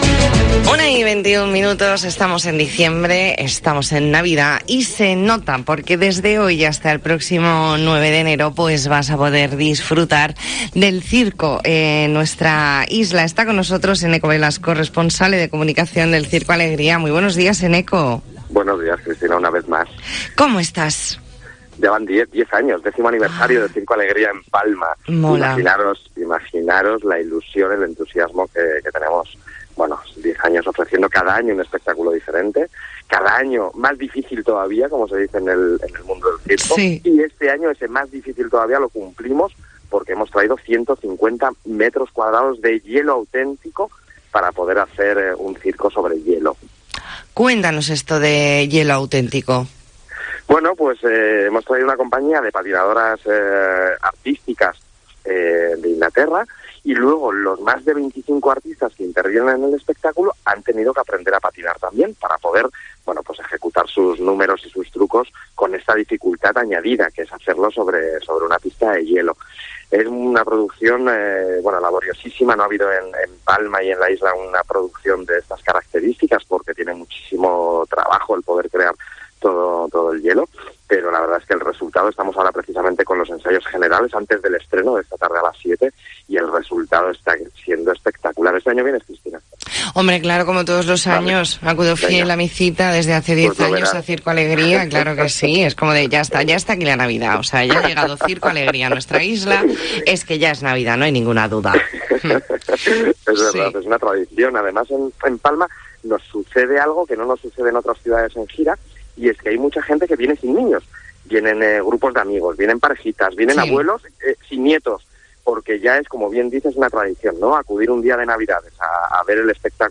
Entrevista en La Mañana en COPE Más Mallorca, viernes 3 de diciembre de 2021.